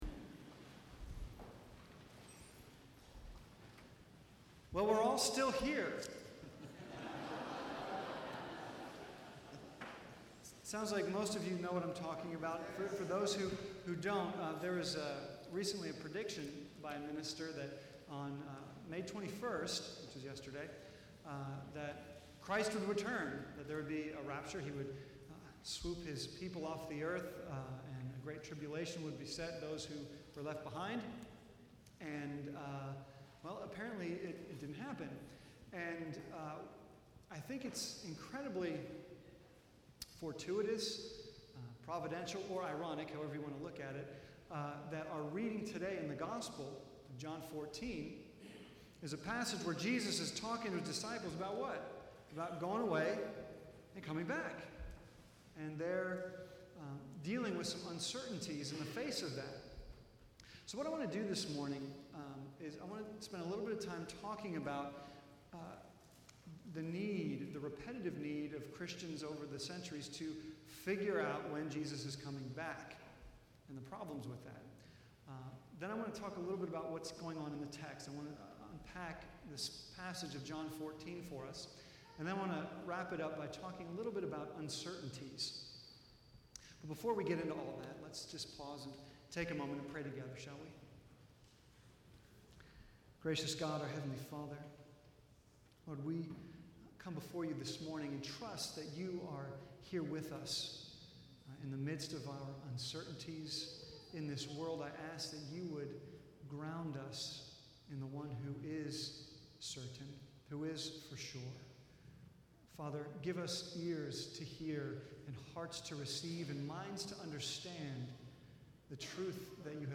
End Of the World (May 22, 2011) A recent "prediction" by a pastor in California that the world would end on May 21st, while not coming true, has certainly raised a lot of questions about what Christians believe about the End Times and Judgement Day. This sermon takes a clear, biblical, and brief look at genuine Christian beliefs about these fascinating concepts.